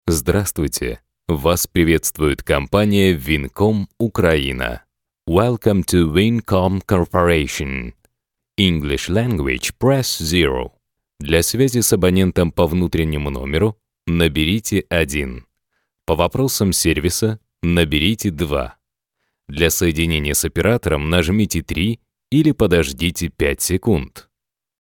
Native Russian voicetalent, narrator, presenter.
Sprechprobe: Sonstiges (Muttersprache):